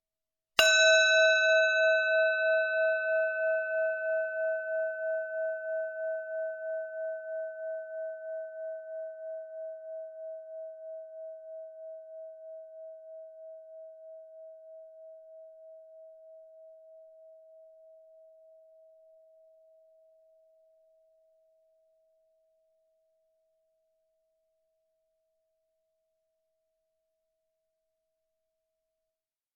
Bronze Bell 2
bell bronze chime clock ding percussion ring stereo sound effect free sound royalty free Sound Effects